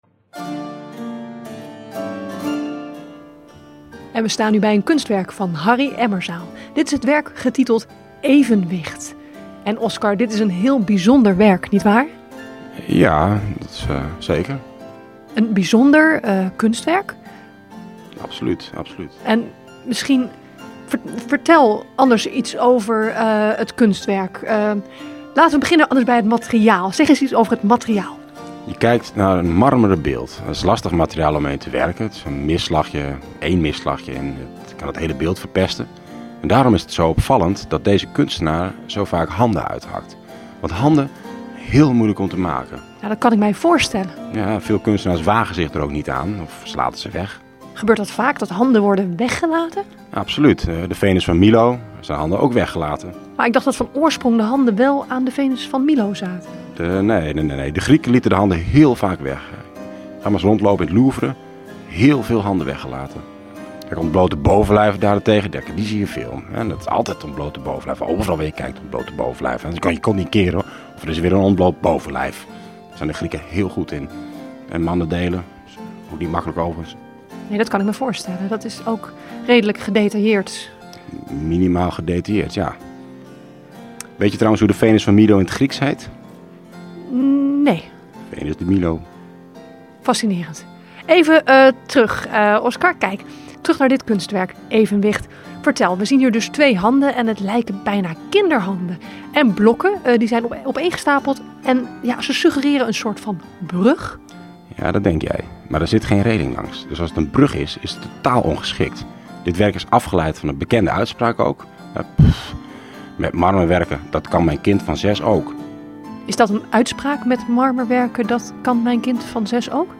Voor deze editie van Rijnhuizen Uitgebeeld is er een audiowandeling met een kwinkslag ontwikkeld, langs een aantal gemarkeerde kunstwerken.